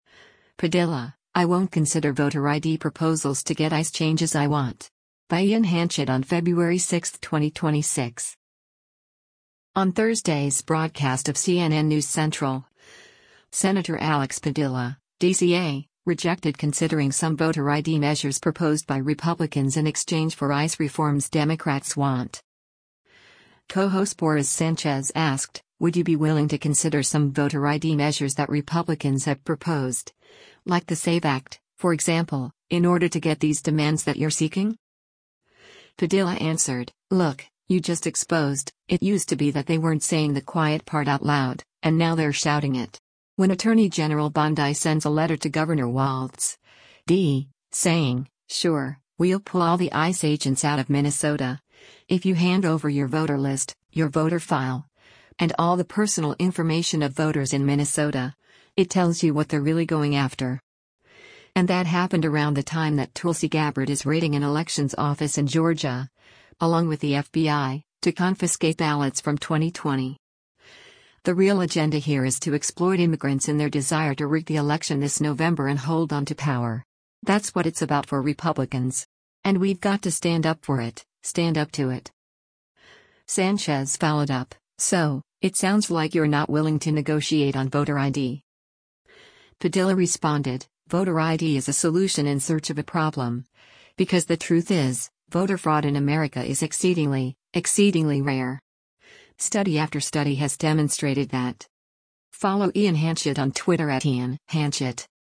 On Thursday’s broadcast of “CNN News Central,” Sen. Alex Padilla (D-CA) rejected considering some voter ID measures proposed by Republicans in exchange for ICE reforms Democrats want.